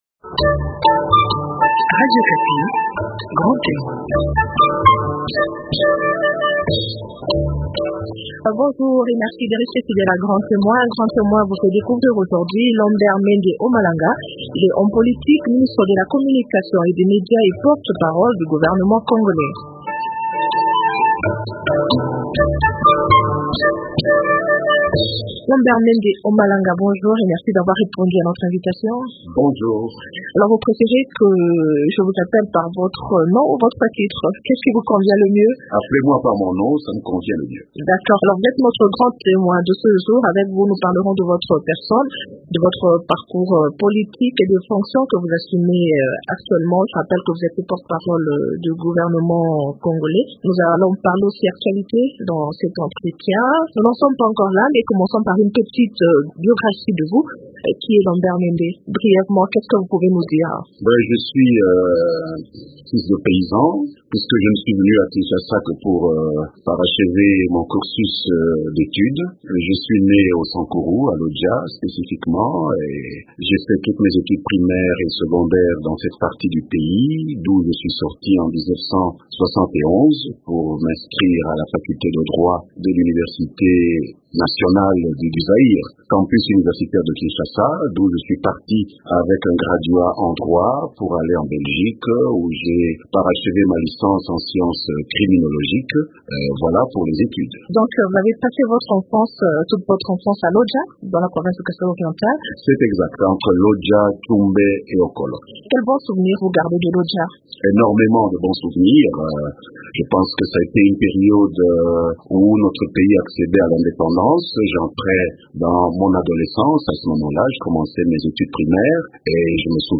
Il s’exprime aussi sur le fonctionnement du gouvernement. Découvrez dans cet entretien sa passion pour la lecture, l’Internet et les activités agricoles.